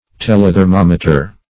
Telethermometer \Tel`e*ther*mom"e*ter\, n. [Gr.